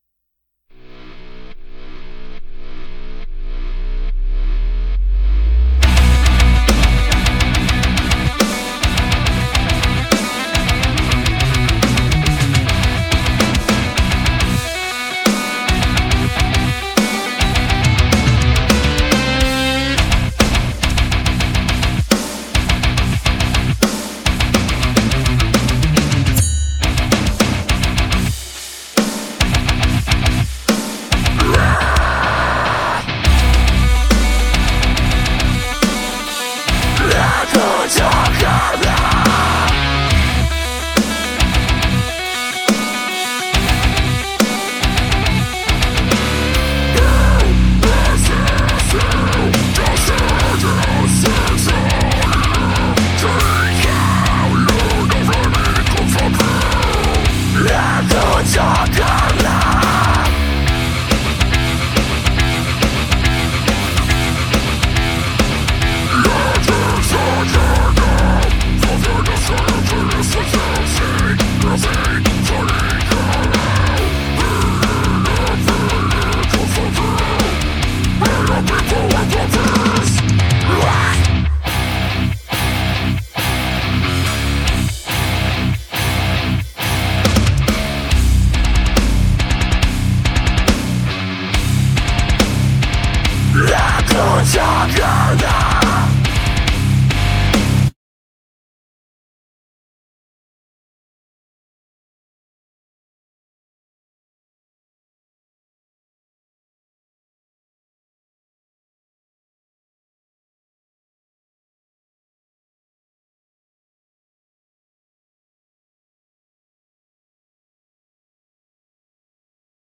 Comedy gold, plus a really confused AI... the triangle *slays* me.